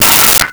Metal Lid 01
Metal Lid 01.wav